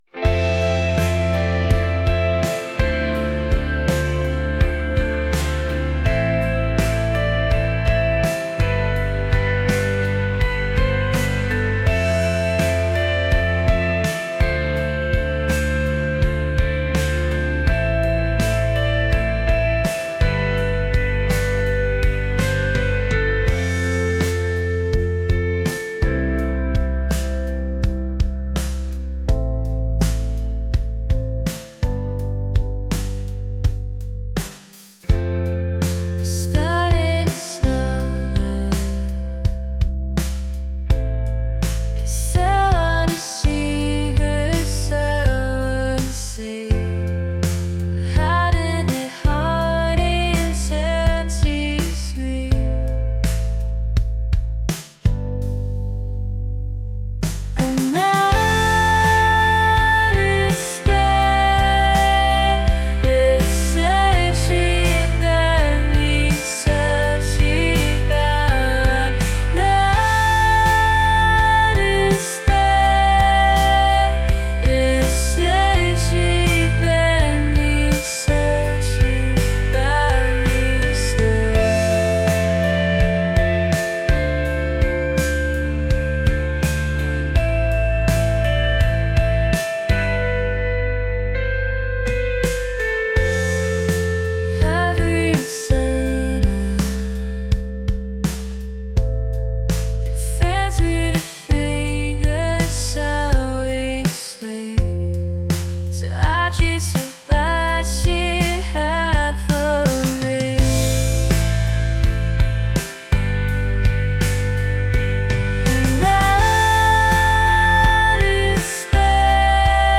ethereal | dreamy | indie | pop